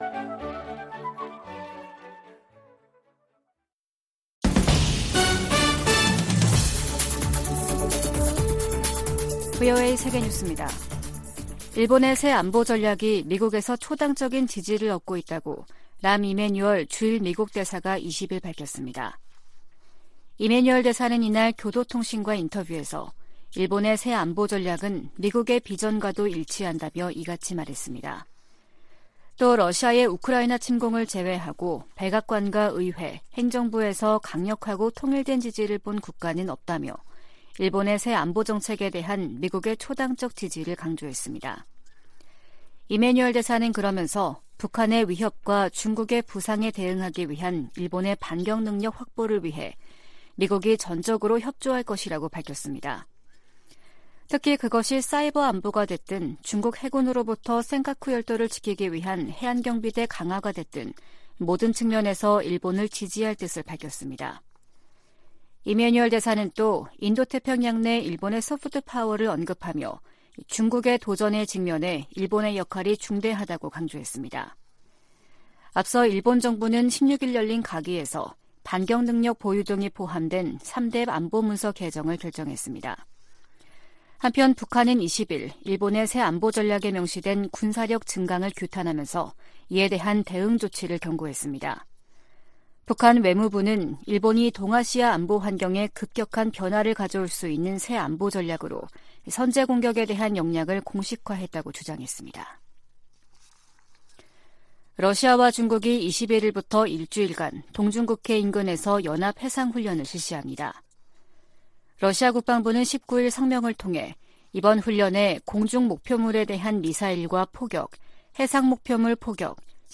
VOA 한국어 아침 뉴스 프로그램 '워싱턴 뉴스 광장' 2022년 12월 21일 방송입니다. 미 국무부는 북한이 정찰위성 시험이라고 주장한 최근 미사일 발사가 전 세계를 위협한다며, 외교로 문제를 해결하자고 촉구했습니다. 유엔은 북한의 최근 탄도미사일 발사와 관련해 한반도 긴장 고조 상황을 매우 우려한다며 북한에 즉각적인 대화 재개를 촉구했습니다.